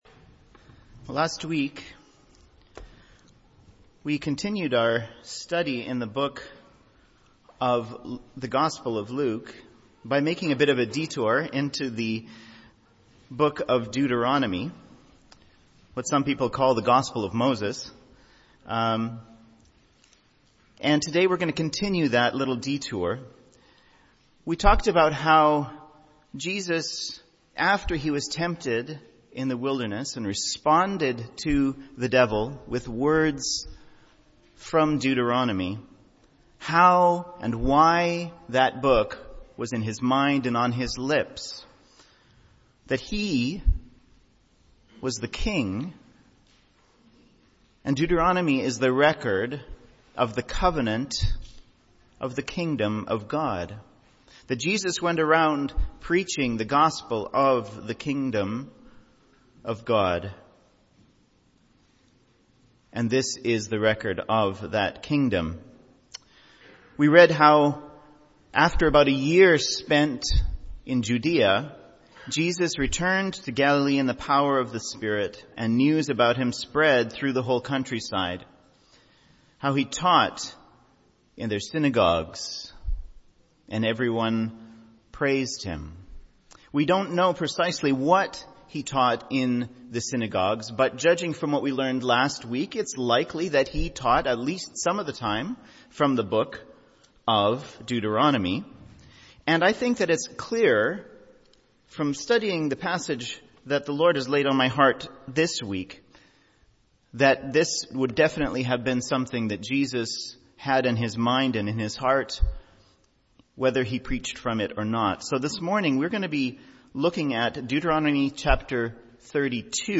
MP3 File Size: 18.8 MB Listen to Sermon: Download/Play Sermon MP3